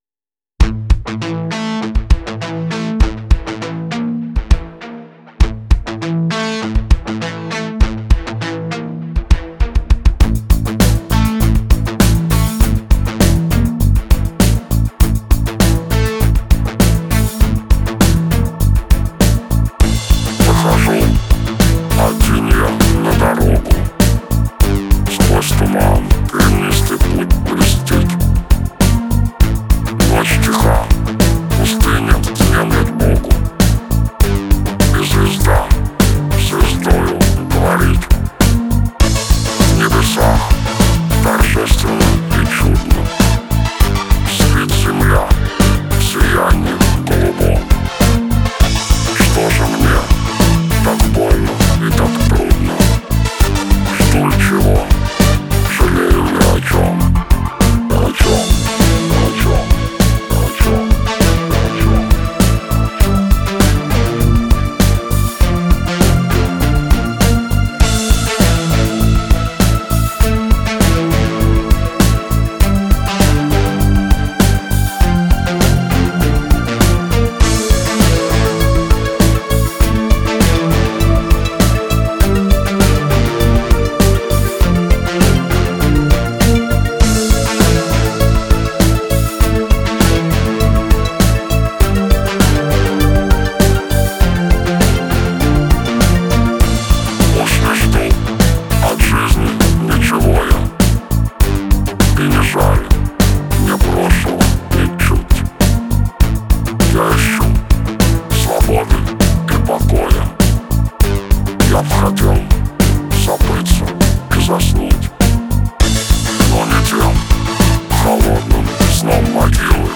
[Release] Выхожу один я на дорогу (простенький меланхоличный индастриал)